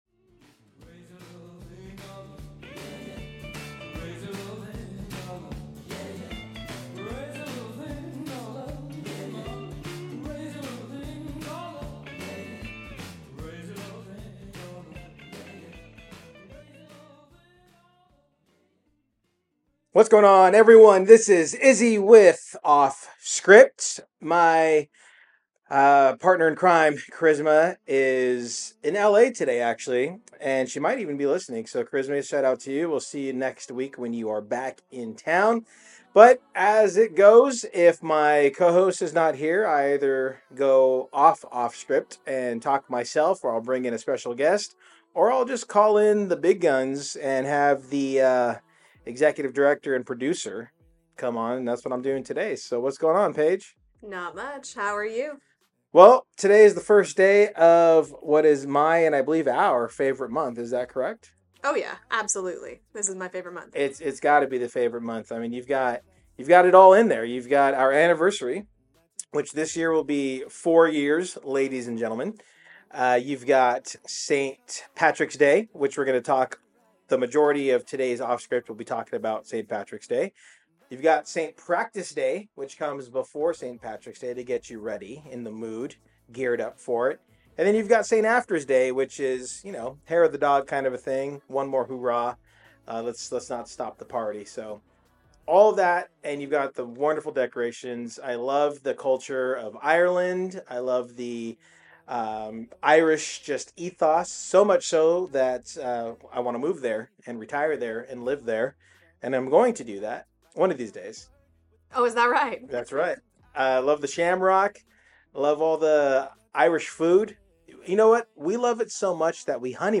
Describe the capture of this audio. This segment of OFF-SCRIPT aired live on CityHeART Radio on Friday March 1, 2024 at lunchtime.